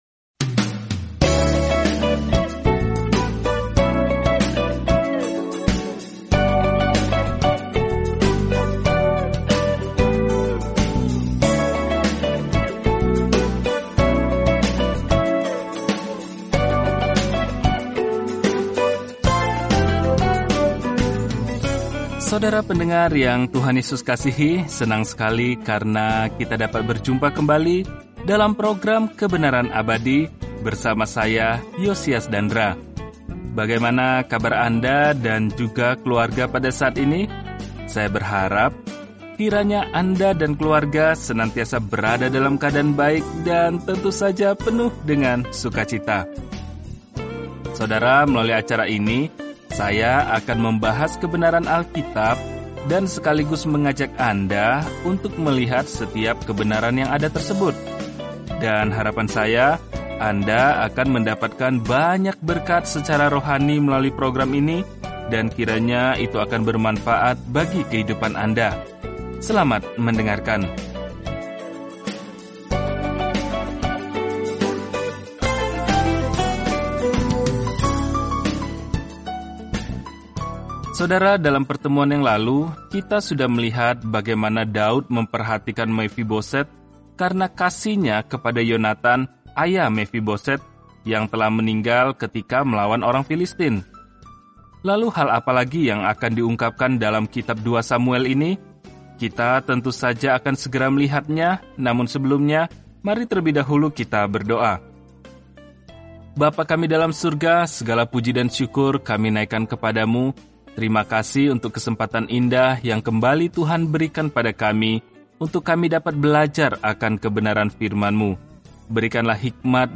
Telusuri 2 Samuel setiap hari sambil mendengarkan pelajaran audio dan membaca ayat-ayat tertentu dari firman Tuhan.